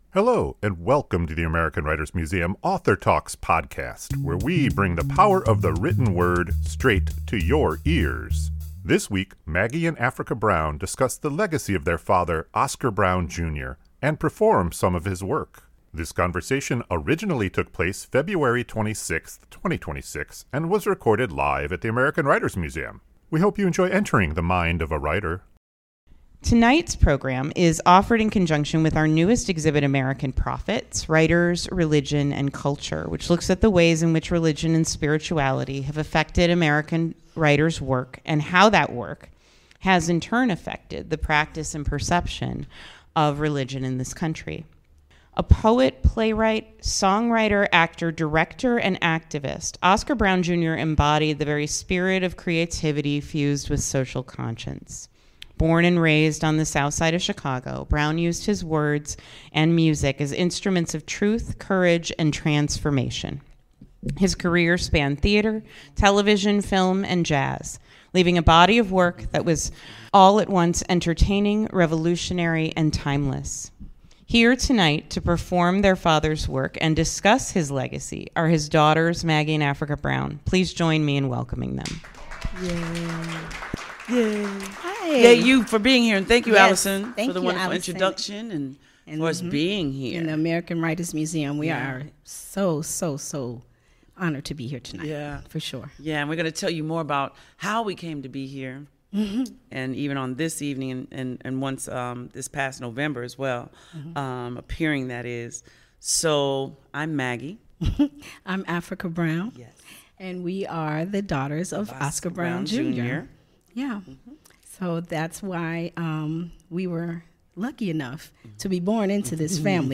This conversation originally took place February 26, 2026 and was recorded live at the American Writers Museum.